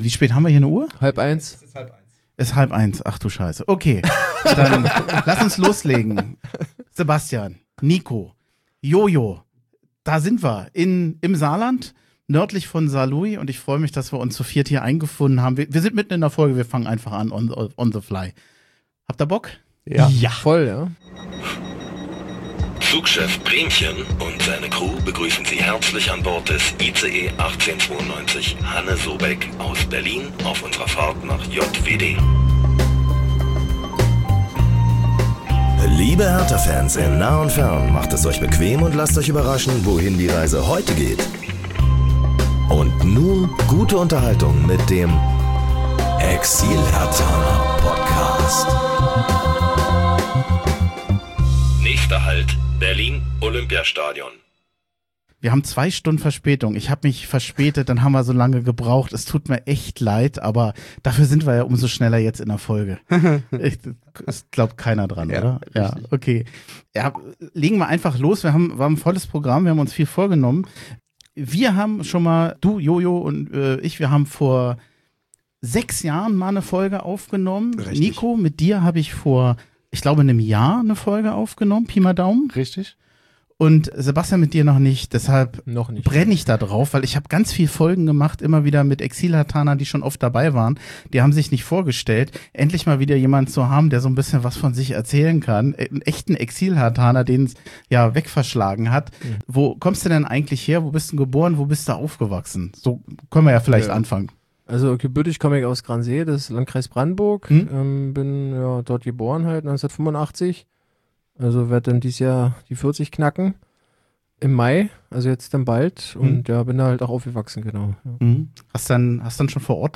Gegensätzlicher kann ein Wochenende kaum sein: Am Samstag besuchten wir noch in bester Stimmung einige Exilherthaner im Saarland zur Podcastaufnahme, am Sonntag dann die schlimme 4:0-Niederlage in Elversberg.